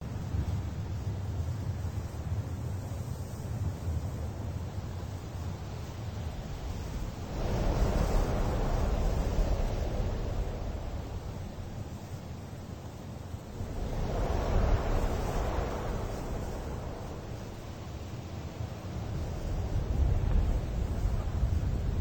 Звуки ветра в пустыне